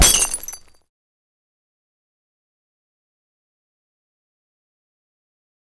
28 Swish With Small Glass Crash.wav